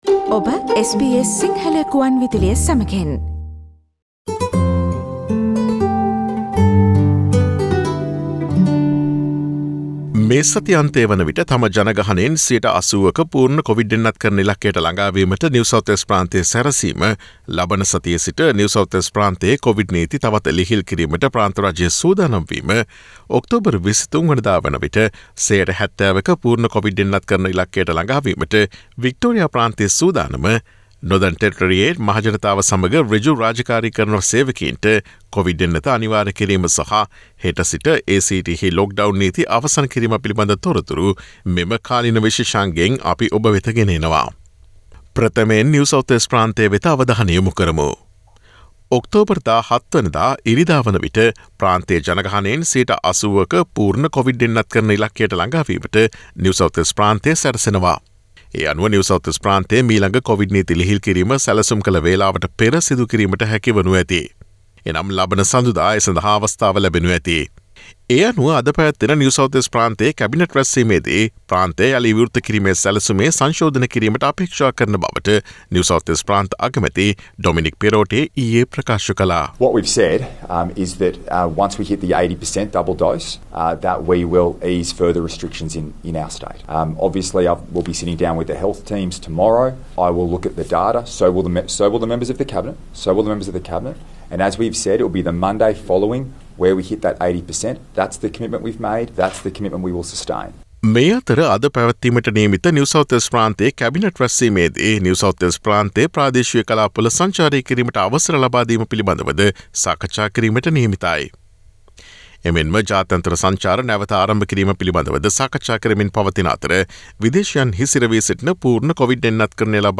නිව් සවුත් වේල්ස්, වික්ටෝරියා, Australian Capital Territory සහ Northern Territory ඇතුළු ඔස්ට්‍රේලියාවෙන් වාර්තා වන නවතම කොවිඩ් තතු විත්ති රැගත් ඔක්තෝබර් 14 වන දා බ්‍රහස්පතින්දා ප්‍රචාරය වූ SBS සිංහල සේවයේ කාලීන තොරතුරු විශේෂාංගයට සවන්දෙන්න.